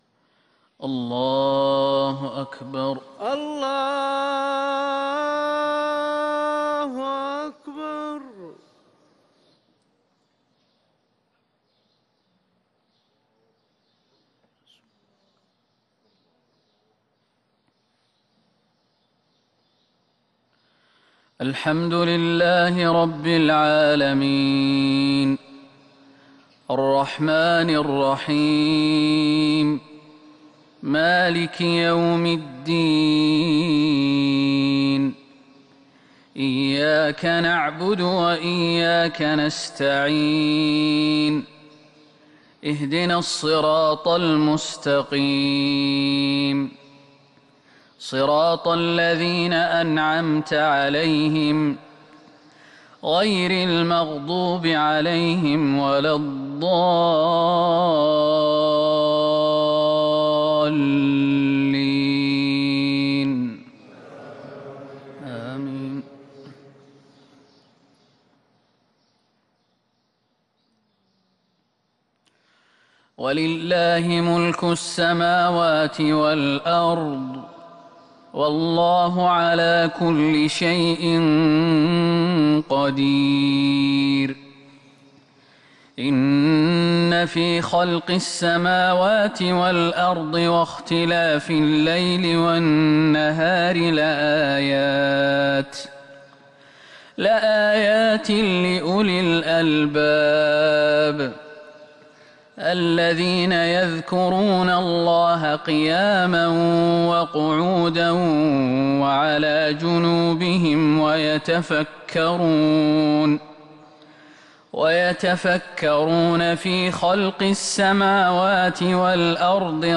فجر الأحد 4-6-1442هـ من سورة آل عمران | Fajr prayer from Surah Aal-i-Imraan 17/1/2021 > 1442 🕌 > الفروض - تلاوات الحرمين